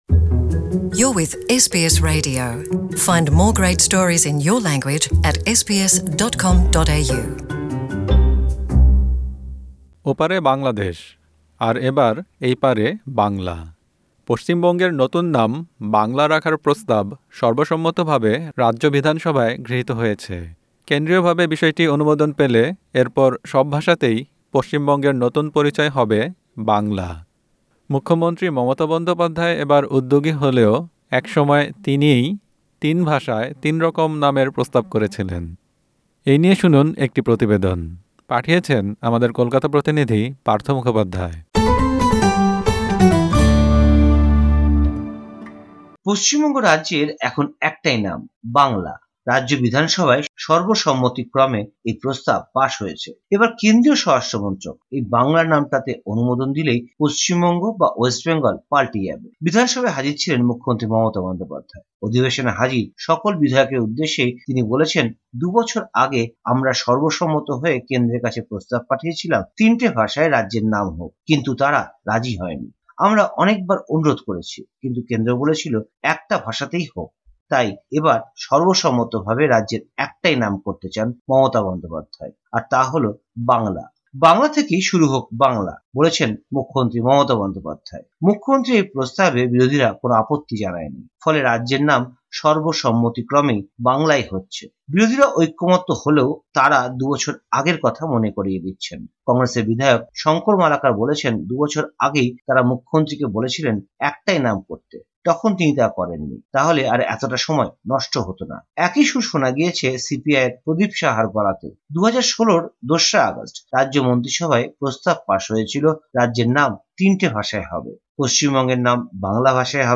প্রতিবেদনটি